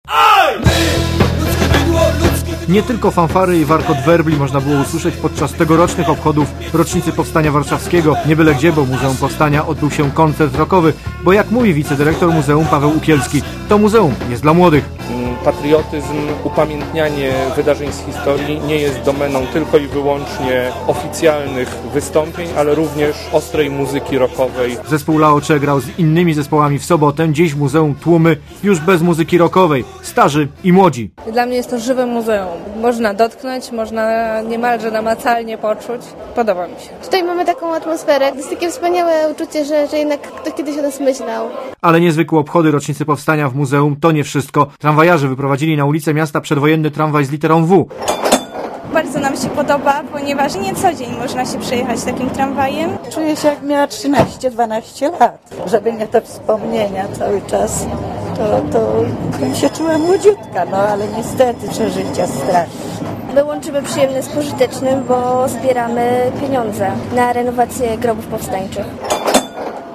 Relacja
obchody_powstania.mp3